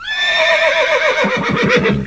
HORSE5.WAV